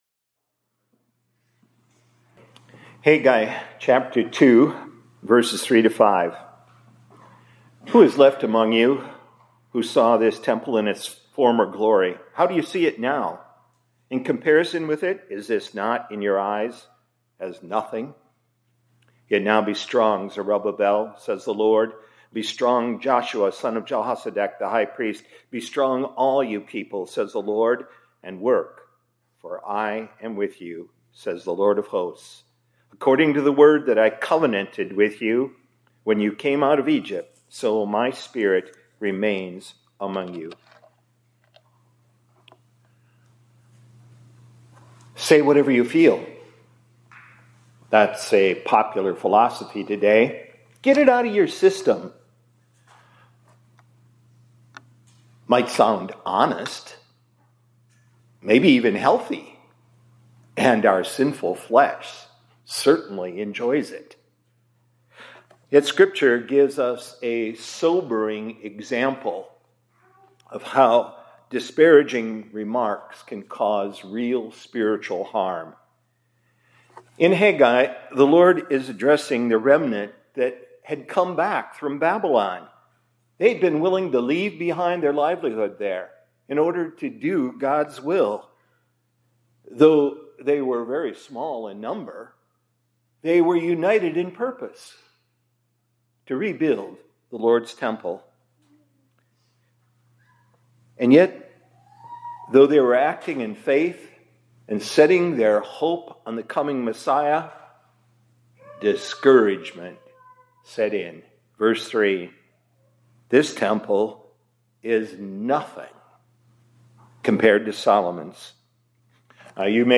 2024-04-12 ILC Chapel — Nothing to Fear